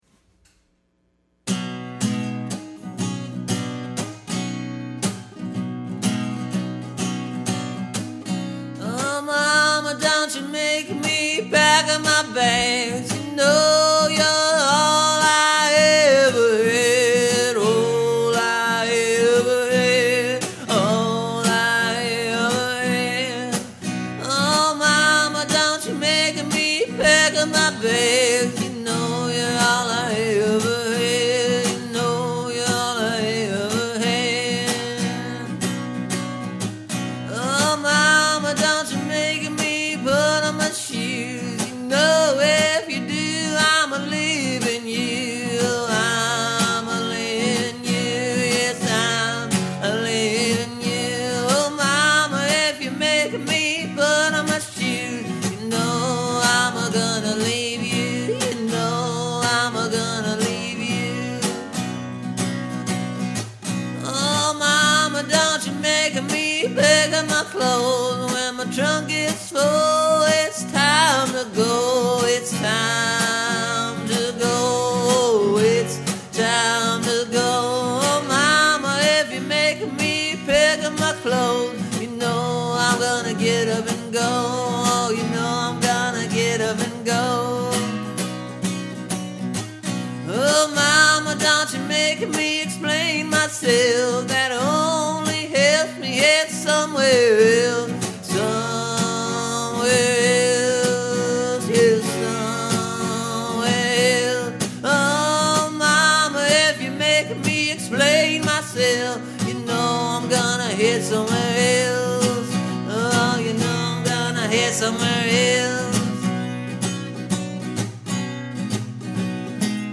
Then I came up with a new first verse so I could sing it in this new style before kickin into the old faster guitar lick.